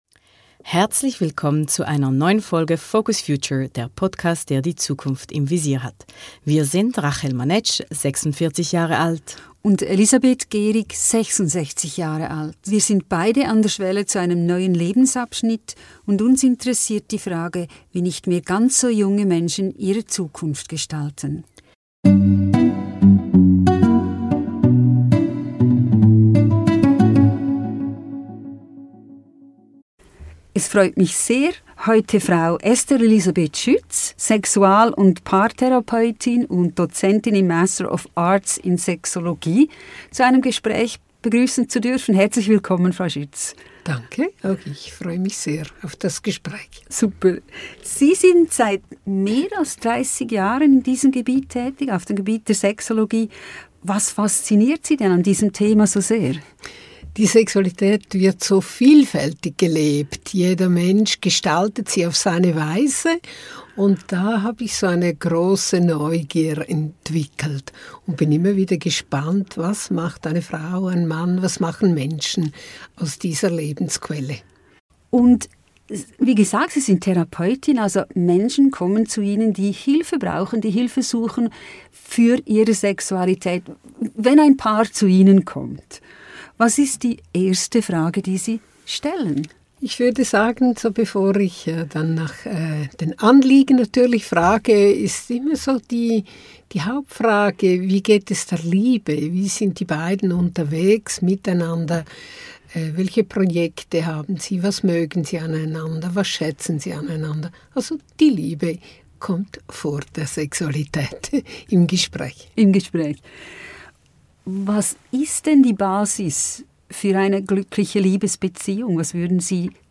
Hört rein in ein unaufgeregtes, offenes und interessantes Gespräch.